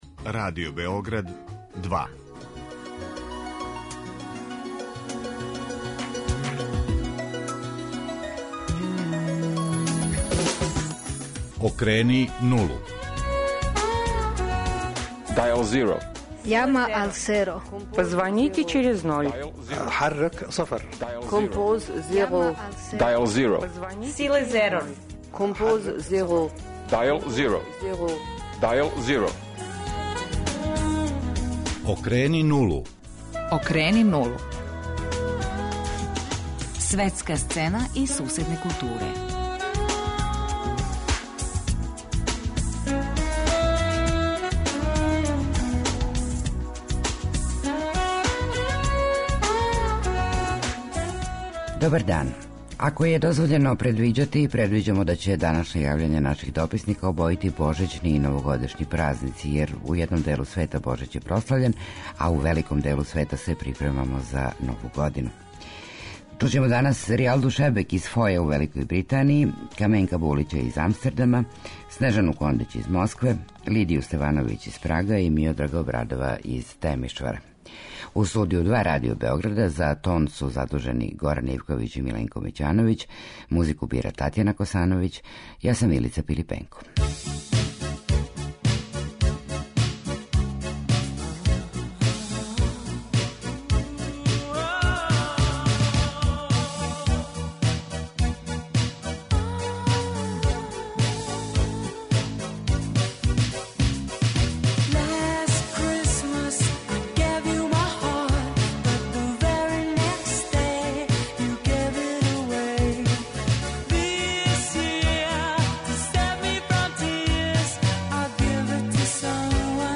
Ни празнична ни претпразнична атмосфера неће спречити дописнике Радио Београда 2 да пошаљу своје извештаје и утиске.